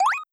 Coin2.wav